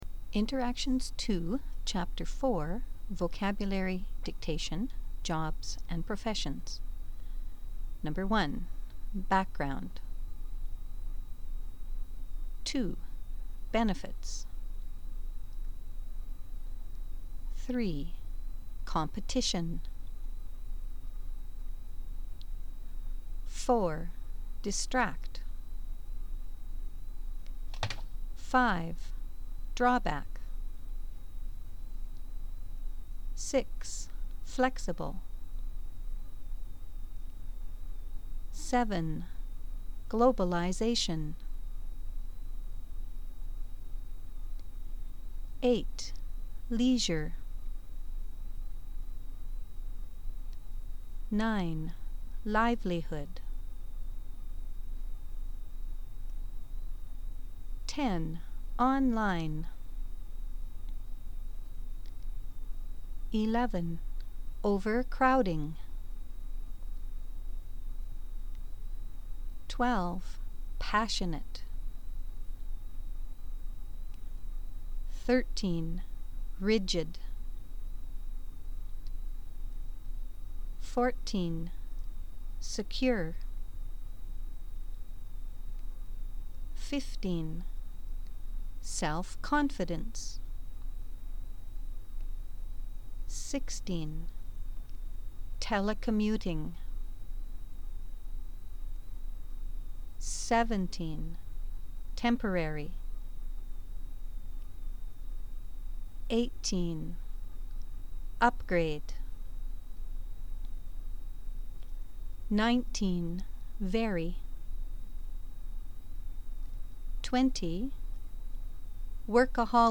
Dictations
First you will hear a list of words.  Then you will hear a paragraph dictation.